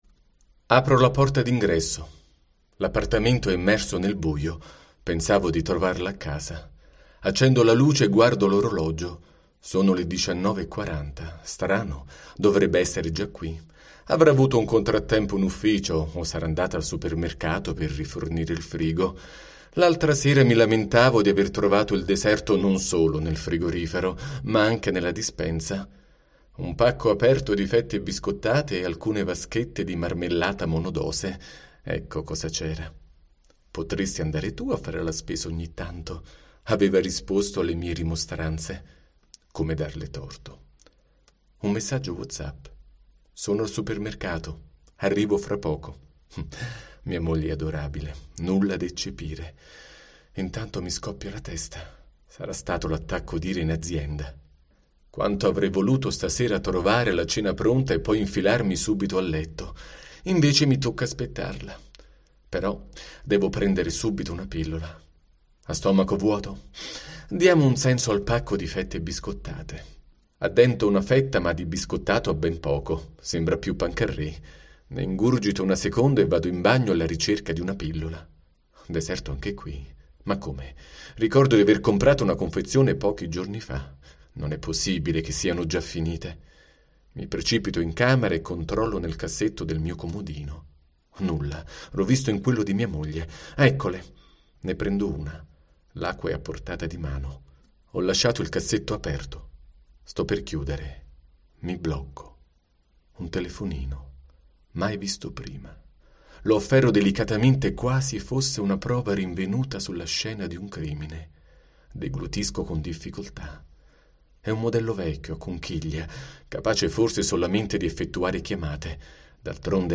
Ascolta in podcast della storia vera: parla un uomo roso dal tarlo della gelosia